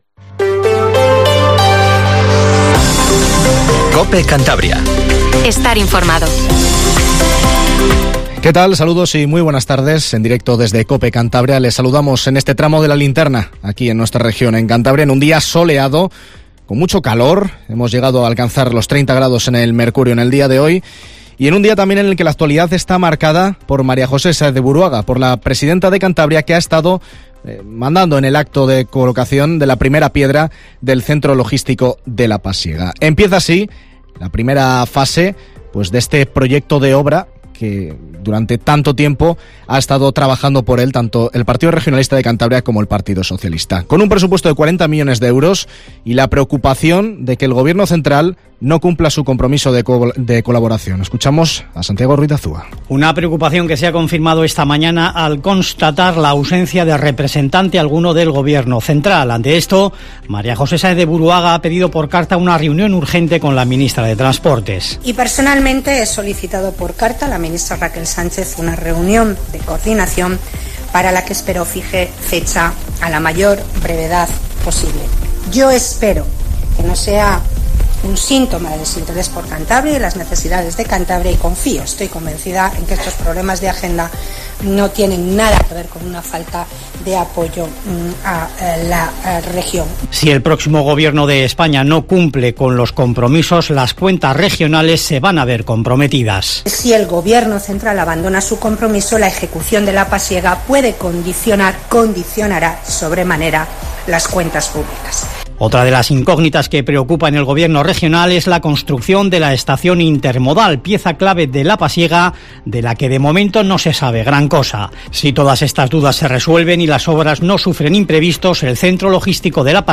Informativo LA LINTERNA en COPE CANTABRIA 19:50